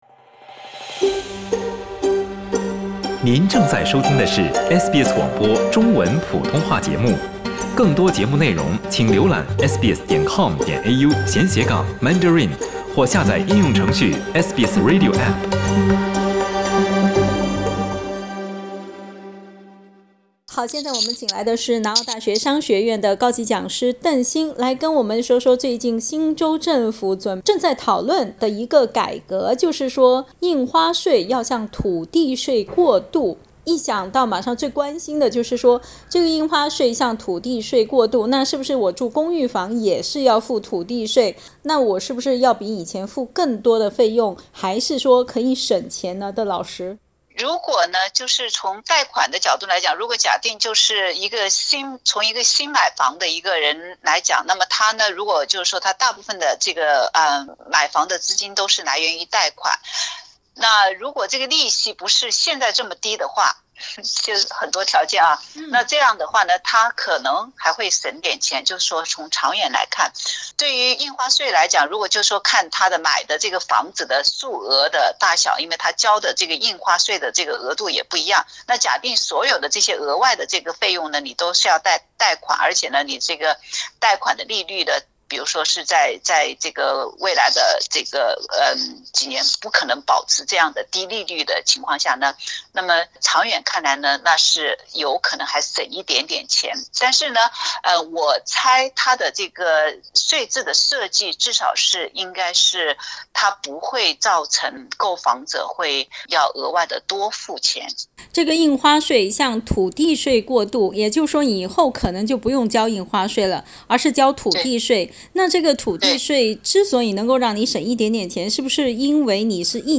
（本节目为嘉宾观点，不代表本台立场。）